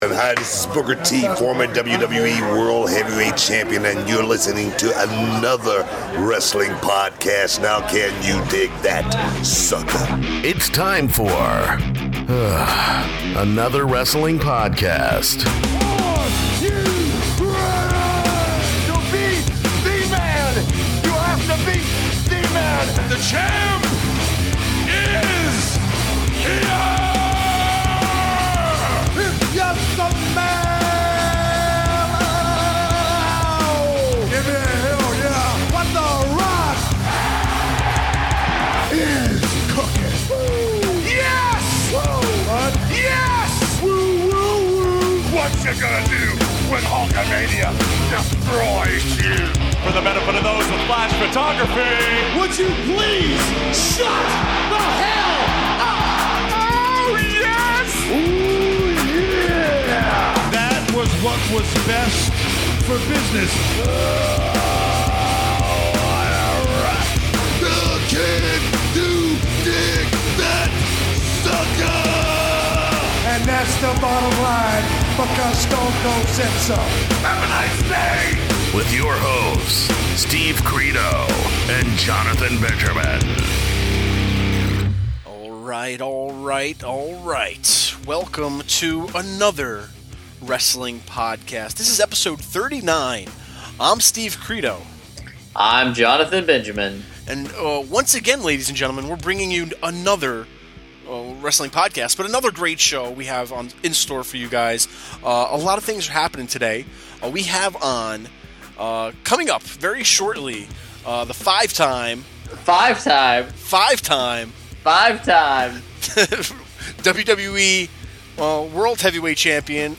In this episode the guys are intrigued about how many families work in the world of pro wrestling, but more specifically the brothers & sisters. They are also joined by WWE Hall of Famer & 5-time 5-time 5-time World Heavyweight Champion Booker T!